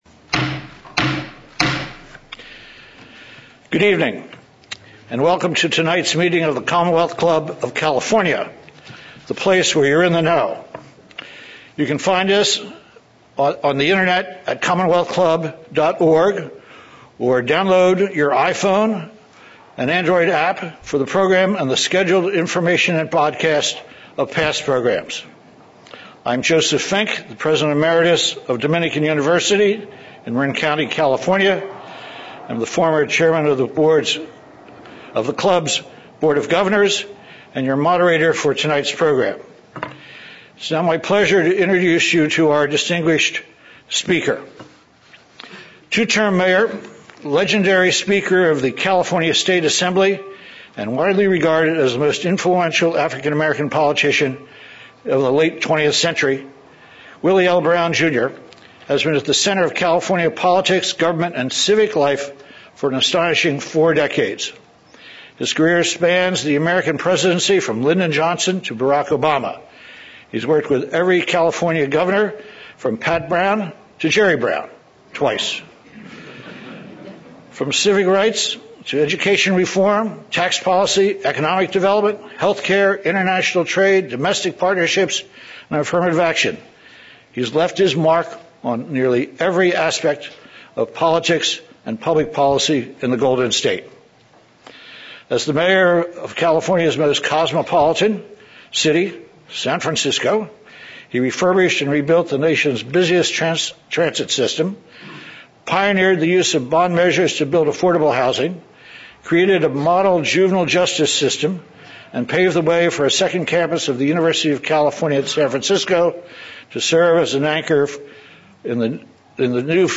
Willie Brown: Annual Lecture on Political Trends
Former Mayor, San Francisco; Former Speaker, California State Assembly